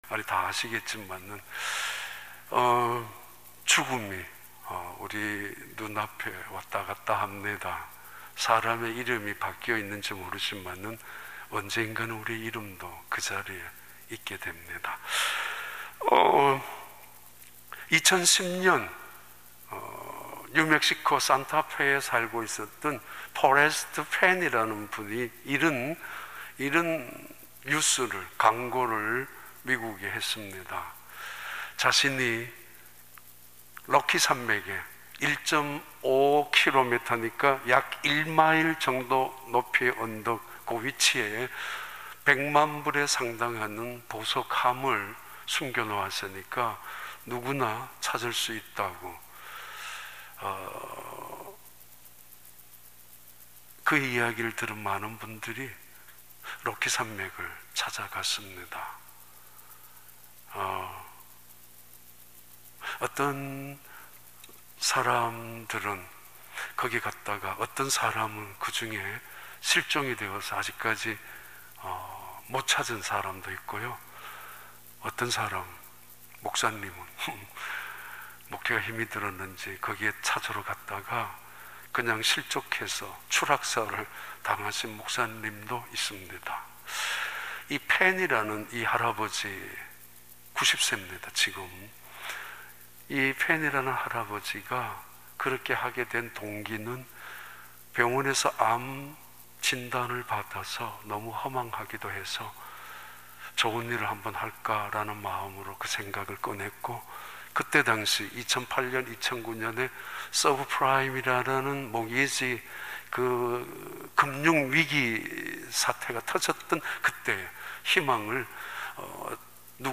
2020년 12월 27일 주일 4부 예배